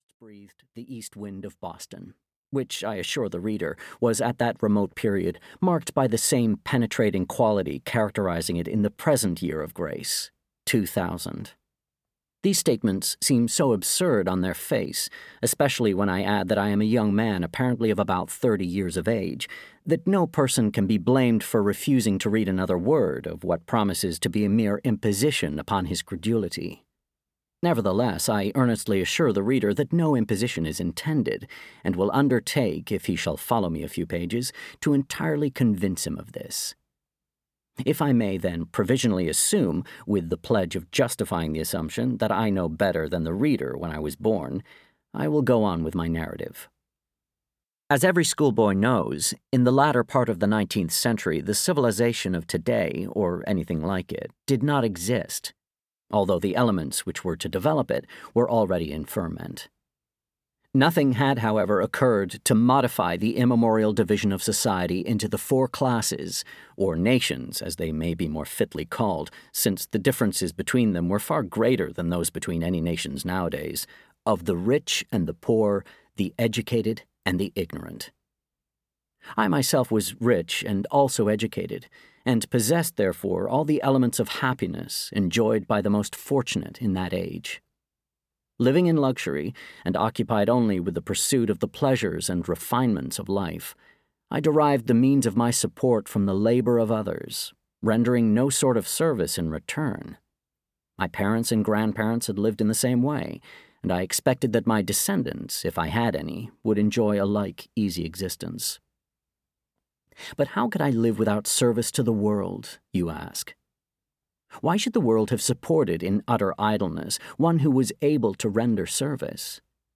Audio knihaLooking Backward: 2000–1887 (EN)
Ukázka z knihy